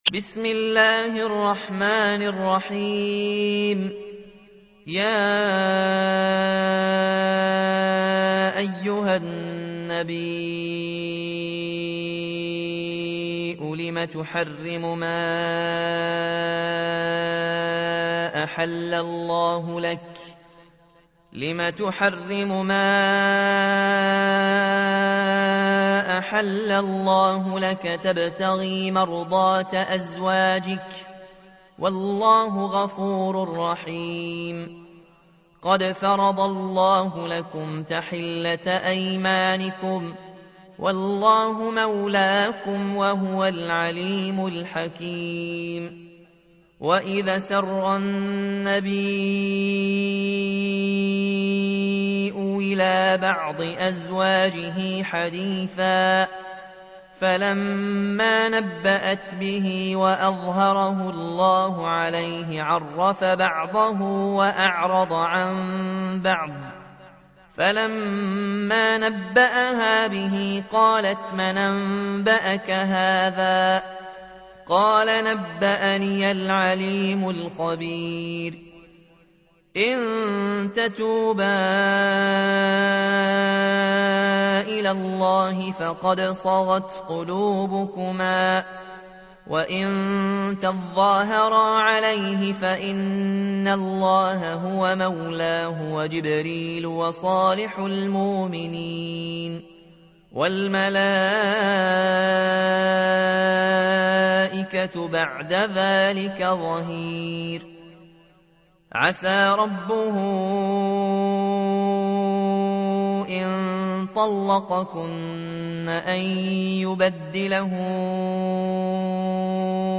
تحميل : 66. سورة التحريم / القارئ ياسين الجزائري / القرآن الكريم / موقع يا حسين